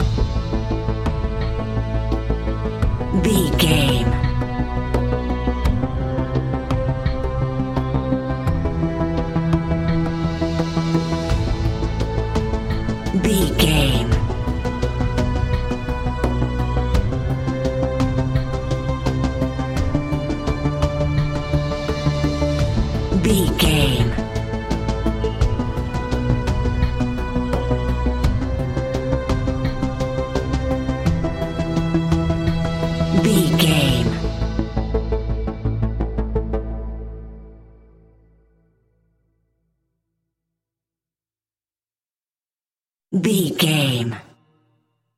In-crescendo
Thriller
Aeolian/Minor
tension
ominous
dark
haunting
eerie
strings
synthesiser
drums
instrumentals
horror music